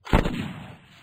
bullet.wav